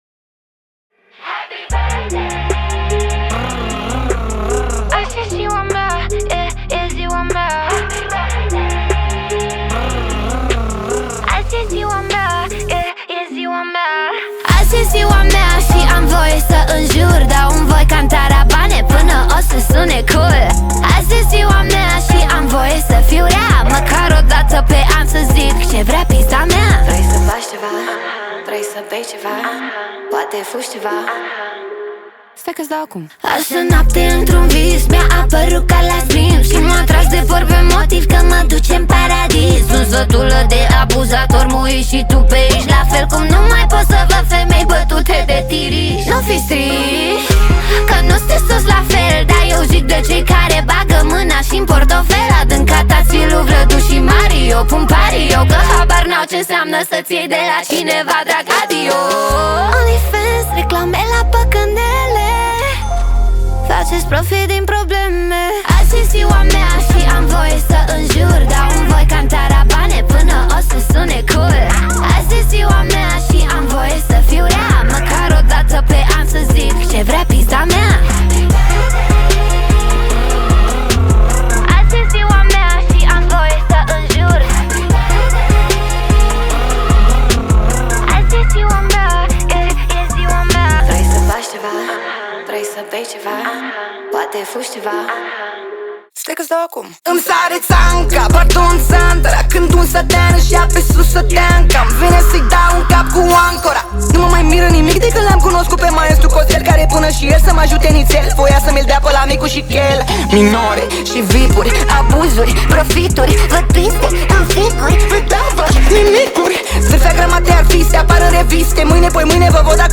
Muzica Usoara